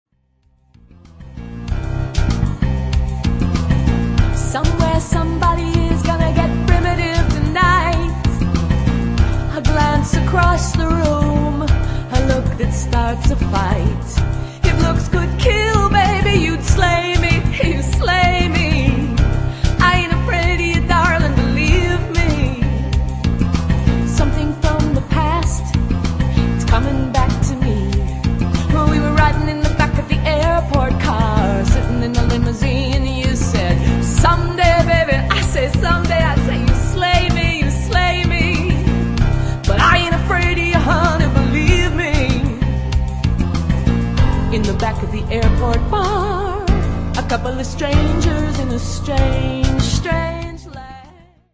original pop music
from folk and pop to jazz influences.